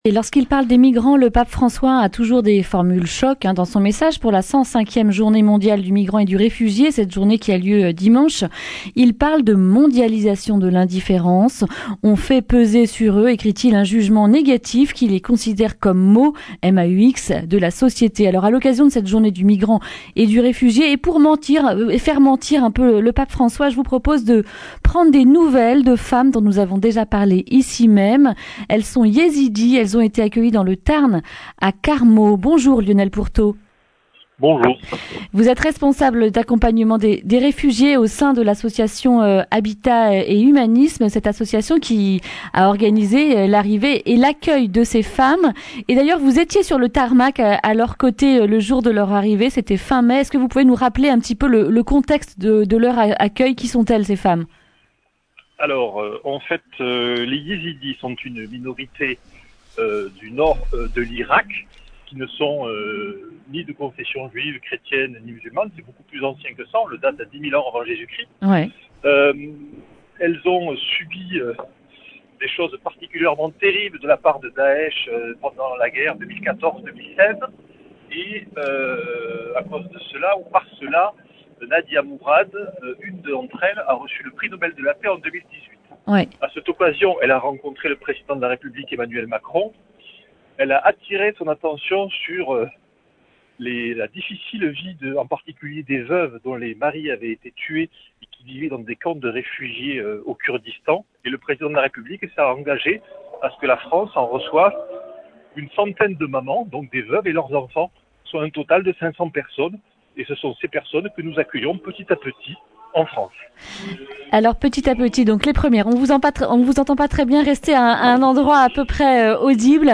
vendredi 27 septembre 2019 Le grand entretien Durée 11 min